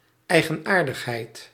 Ääntäminen
IPA: /ˈaɪ̯ɡənhaɪ̯t/